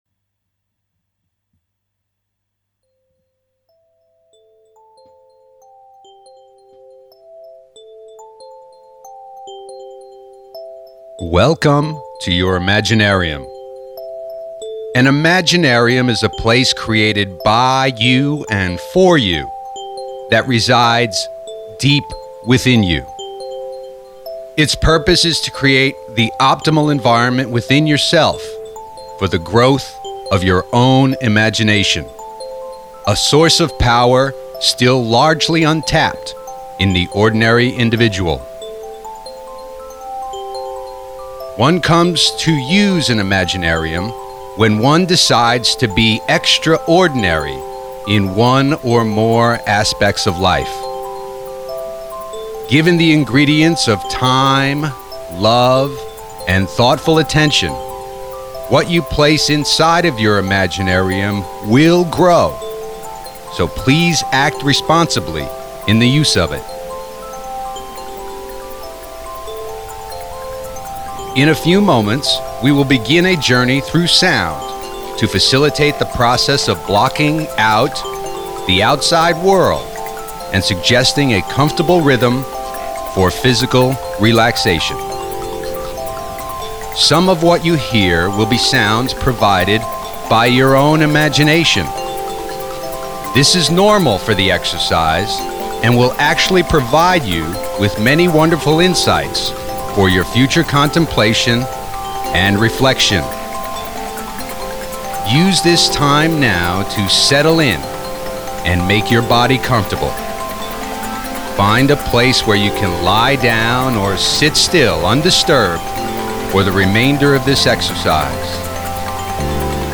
IMAGINARIUM is a vocally guided journey through a unique soundscape that features the use of tones manipulated through Binaural Beat Technique™. These are stereo sounds that create an imaginary sound pattern that seems like a beat but is not actually on the recording.
Binaural beats are reported to work best through the use of stereo headphones.